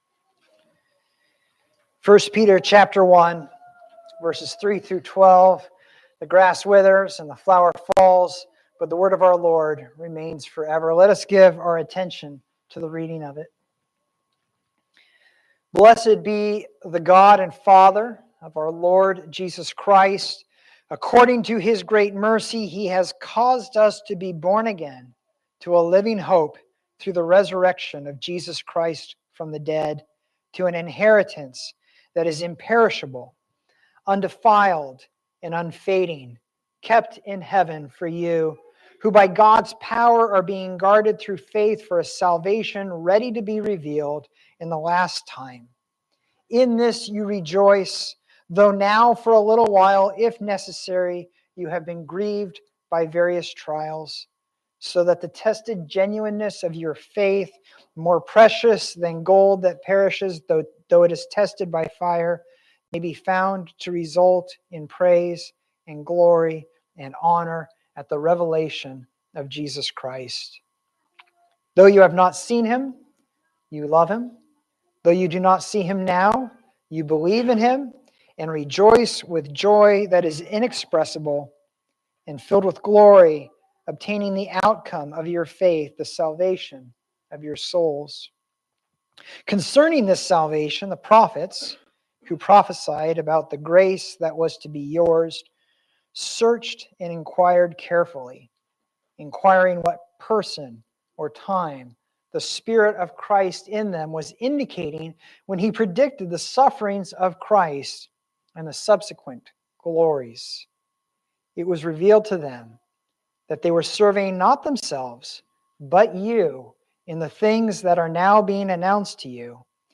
The Road To Glory Reformation Presbyterian Church – Sermons podcast